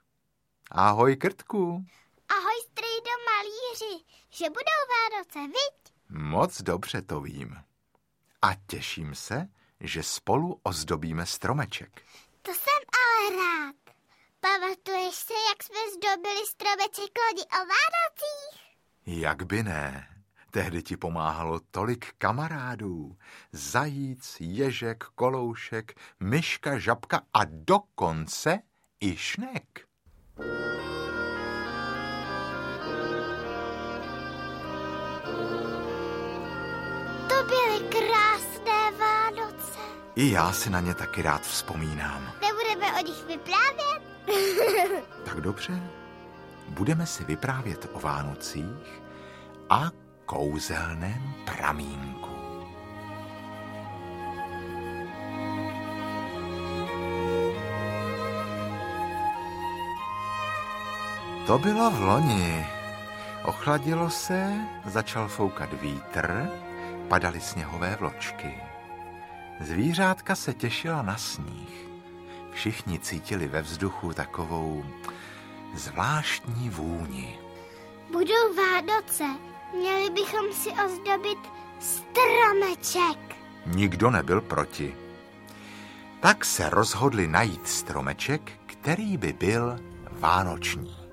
Krtečkova dobrodružství 5 - Krtek a kouzelné Vánoce audiokniha
Zatím poslední z řady Krtečkových příběhů, v milé interpretaci Marka Ebena a Aničky Slováčkové. Krtek objeví kouzelný pramen, ze kterého každý, kdo se napije, zpívá jako slavík
Ukázka z knihy